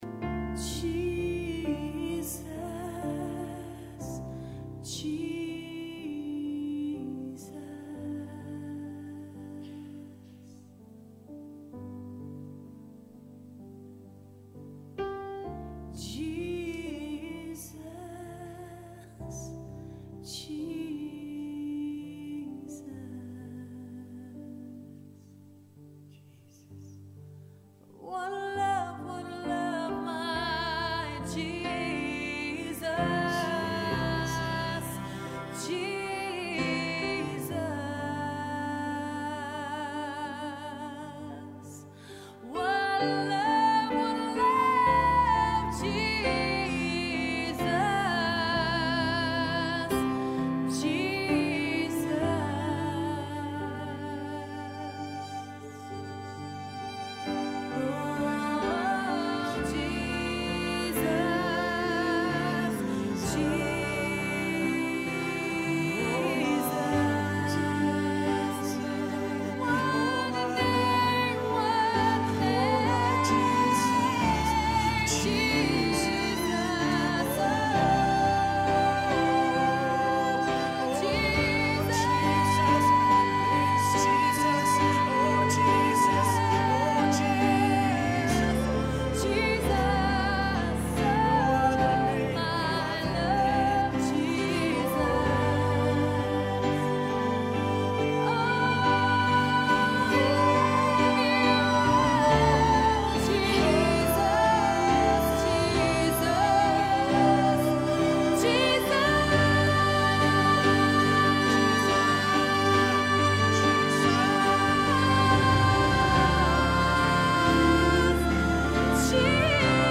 The worship song